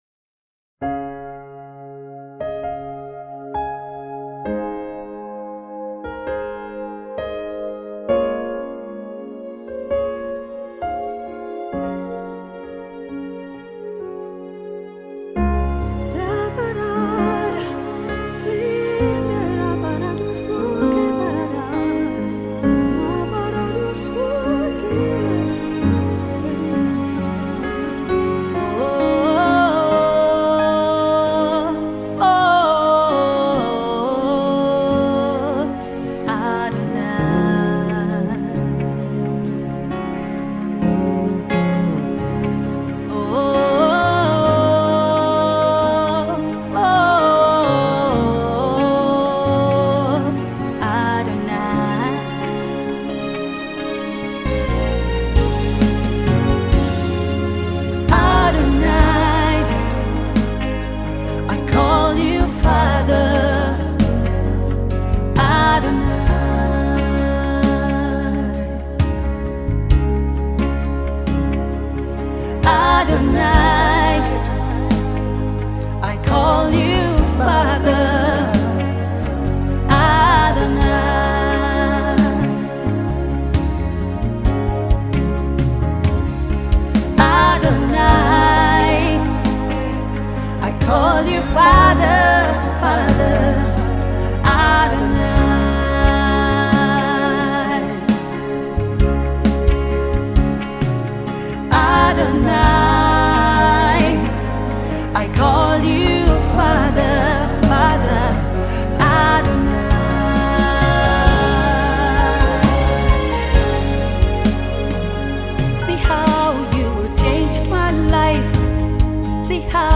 Versatile Gospel music minister